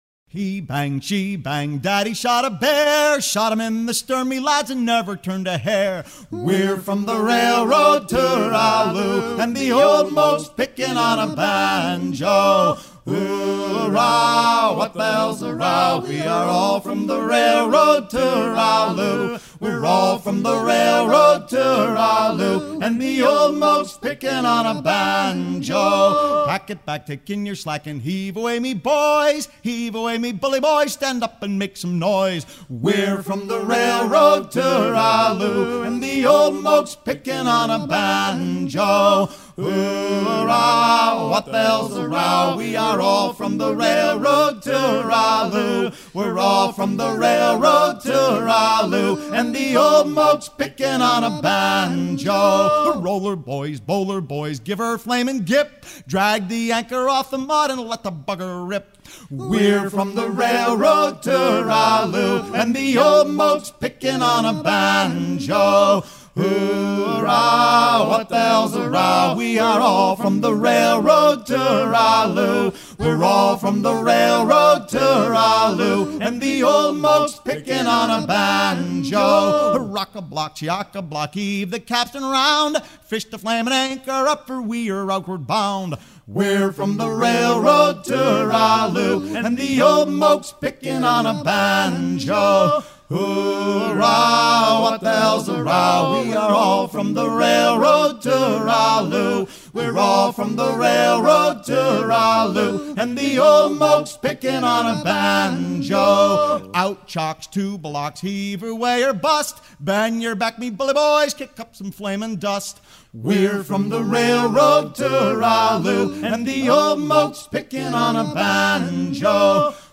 gestuel : à virer au cabestan
circonstance : maritimes
Pièce musicale éditée